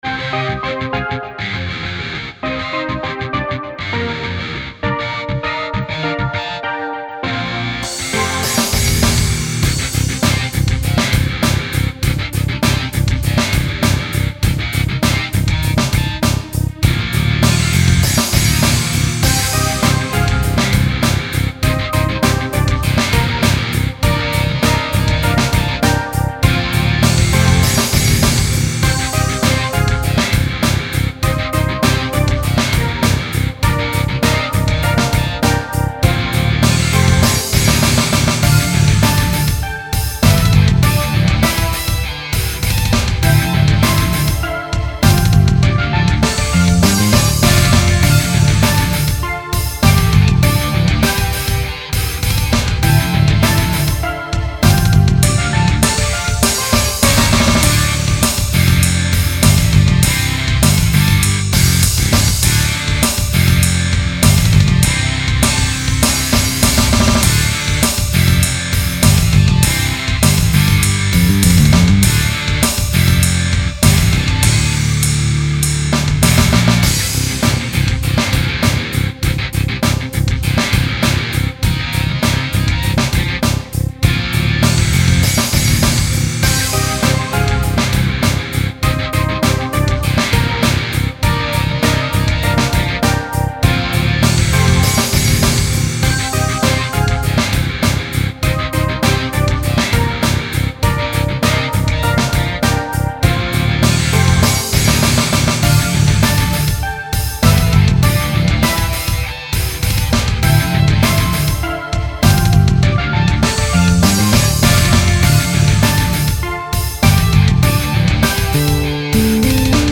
Ein reines MusicMaker-Arrangement, dass ich mir über Kopfhörer sehr gern anhöre, das im Auto oder aber über große Boxen zu statisch, punktuell zu sehr in der Mitte und total unausgewogen klingt. Volumen und Dynamik fehlen und - obwohl ich schon stundenlang versucht habe, es mit einigen Mixing-Programmen "richtig" klingen zu lassen - fehlt mir das Talent, mehr aus dem Song herauszuholen. Genre ist Rock / Hardrock, ein Instrumental, dem zwar die Vocals fehlen, aber trotzdessen mit dem richtigen Mixing cool klingen könnte.